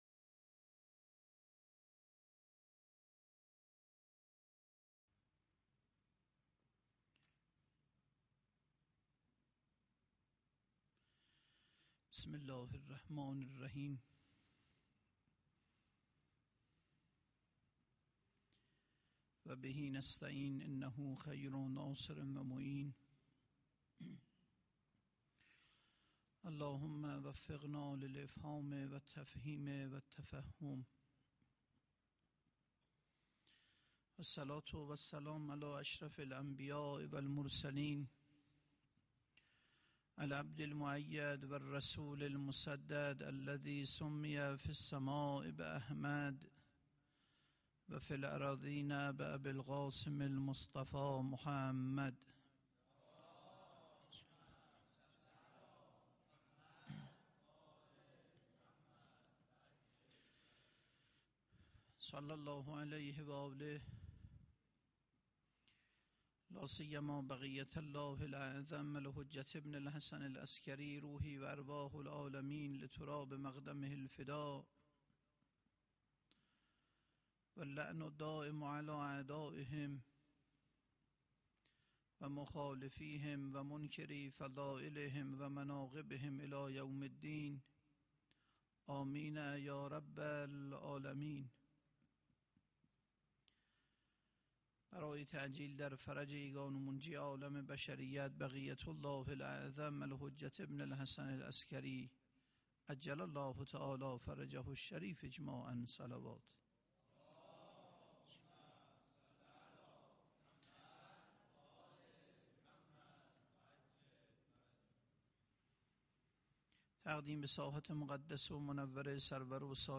سخنرانی7.mp3